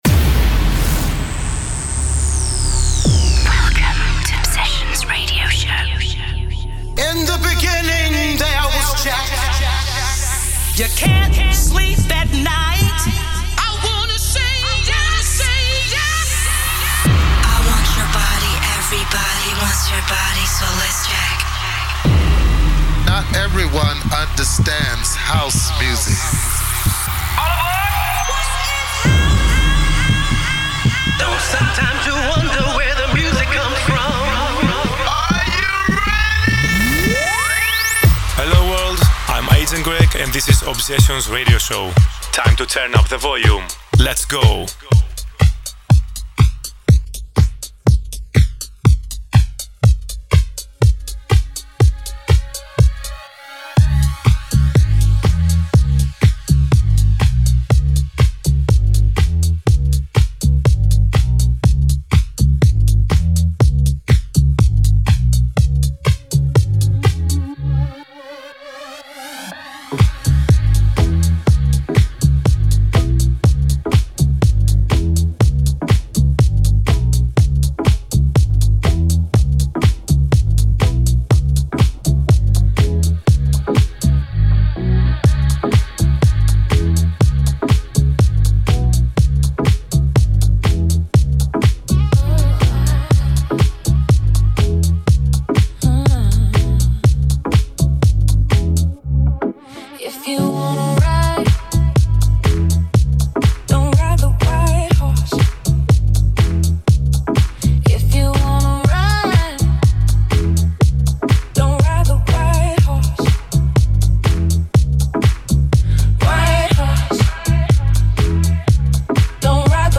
weekly 1 hour music mix
Expect nothing but pure House music.